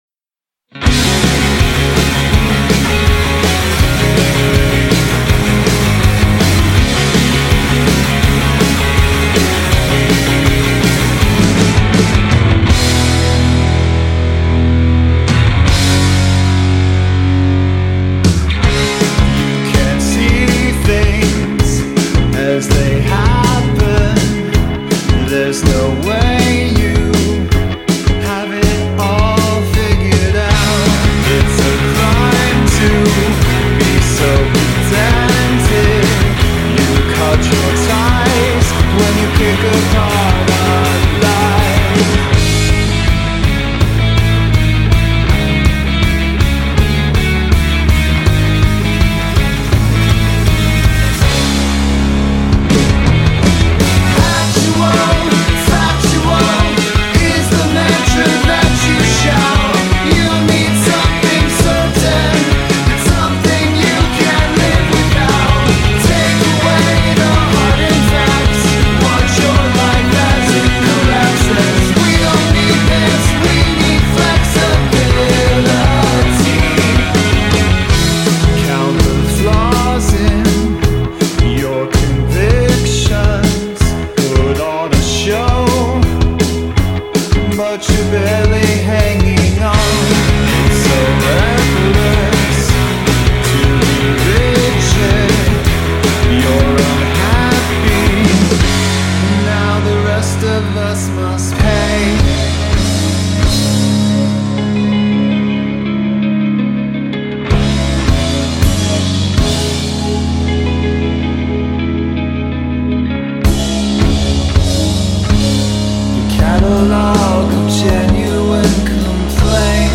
guitar/keys
drums
bass Web Site